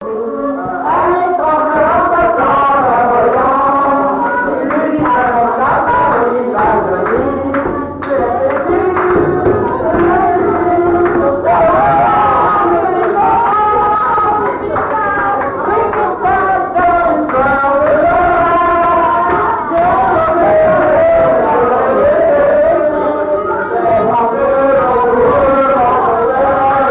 danse : marche
Genre strophique
Pièce musicale inédite